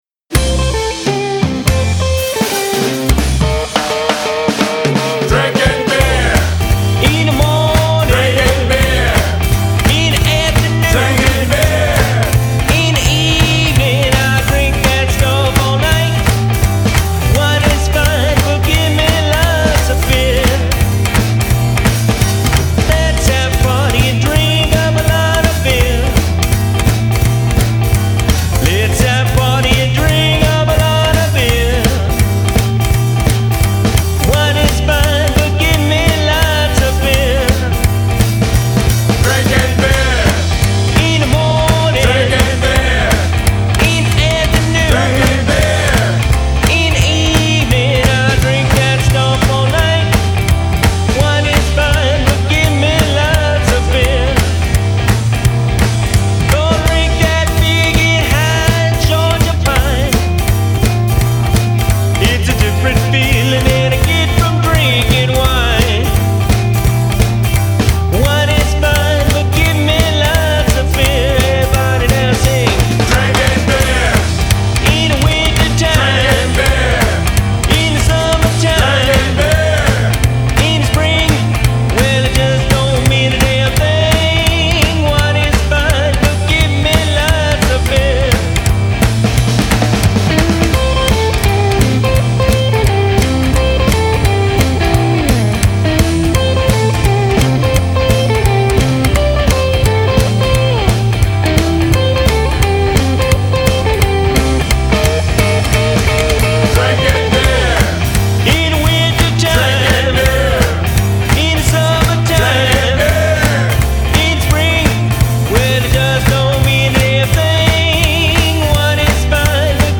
Vocals
Bass
Drums